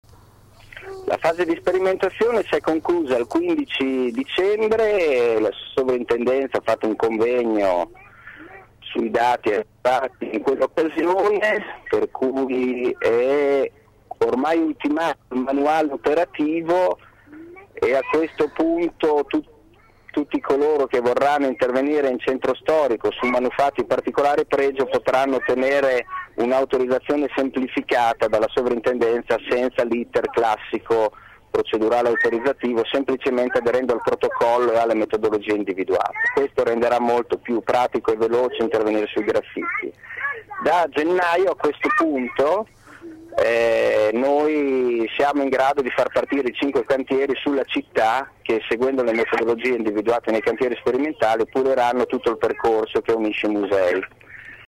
Microfono aperto stamattina in radio con il  Vicesindaco Claudio Merighi rimasto vittima, come altre migliaia di bolognesi, del traffico in tilt. Con un problema in più: la scuola dei figli aveva la caldaia rotta per il gelo, quindi si è presentato in studio coi suoi due bambini (potete ascoltarne la voce in sottofondo…).